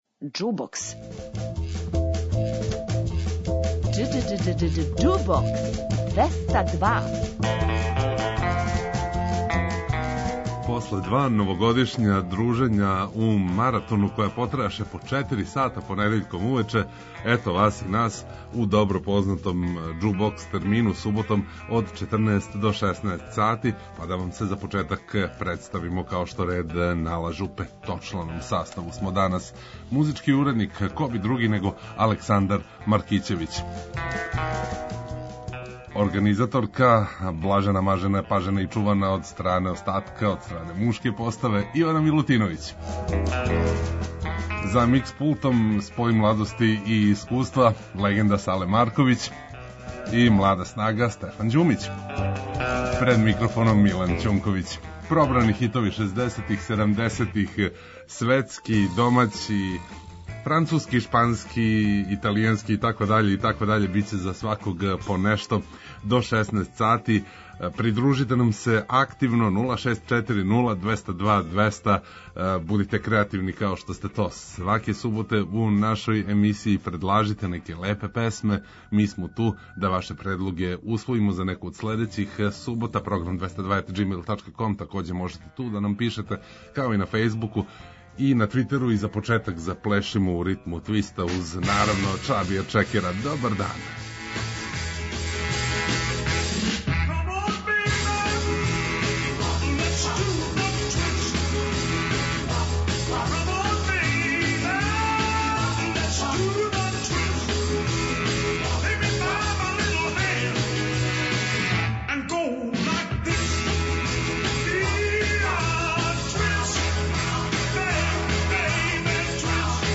Уживајте у пажљиво одабраној старој, страној и домаћој музици.